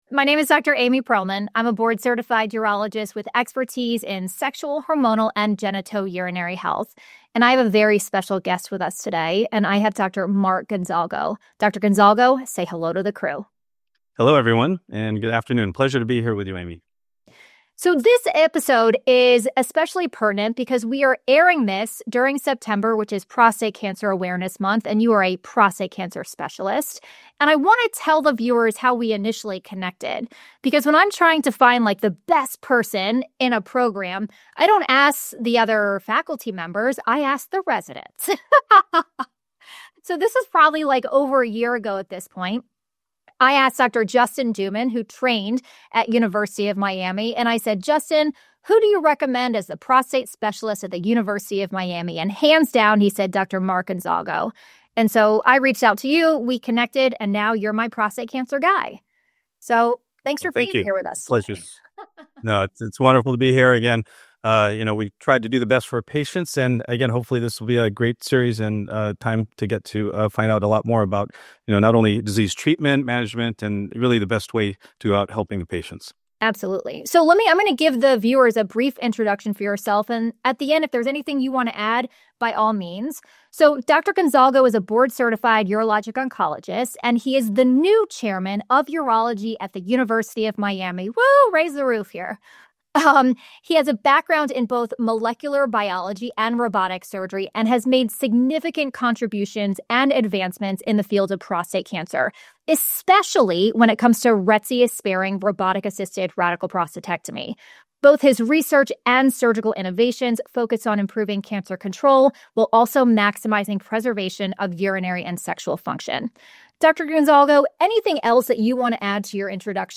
Both physicians stress the value of keeping patients anabolic and active before surgery to support postoperative recovery.